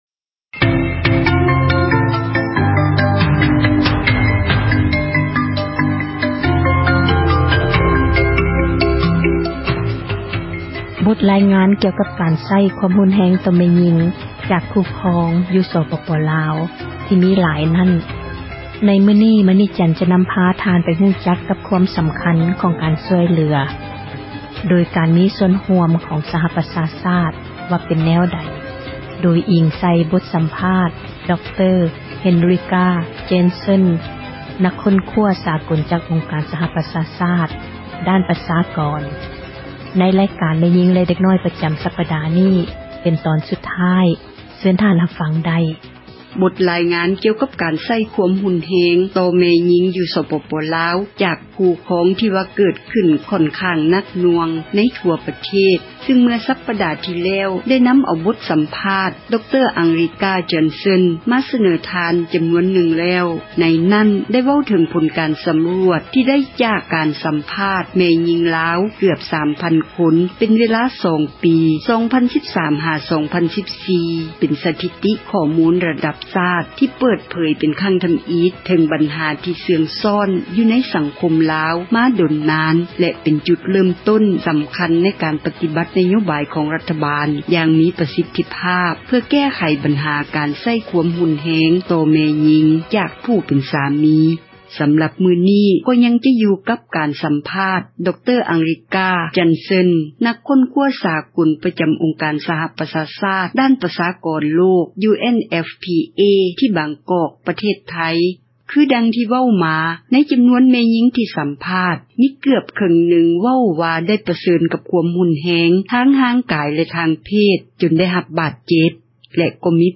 ໃນ ບົດຣາຍງານ ກ່ຽວກັບ ການໃຊ້ ຄວາມຮຸນແຮງ ຕໍ່ ແມ່ຍິງ ຈາກ ຄູ່ຄອງ ຢູ່ ສປປລາວ ທີ່ ມີຢ່າງ ຫຼາກຫຼາຍ ນັ້ນ.